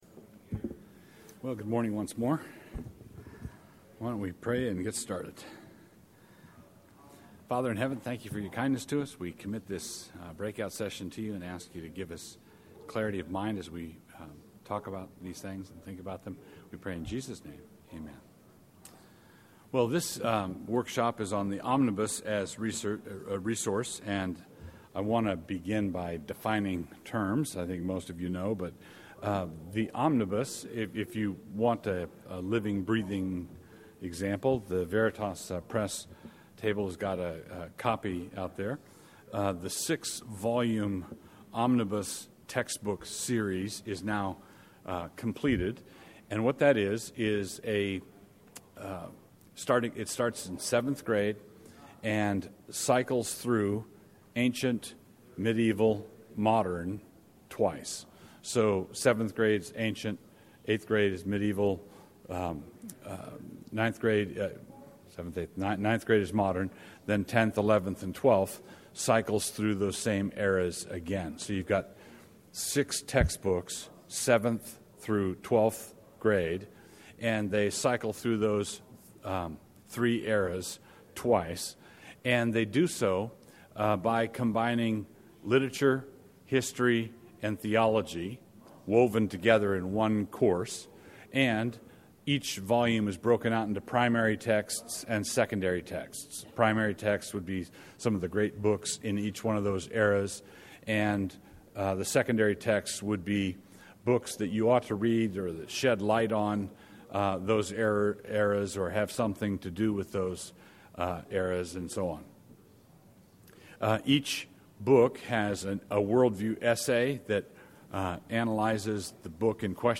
2012 Workshop Talk | 0:59:12 | All Grade Levels, History, Literature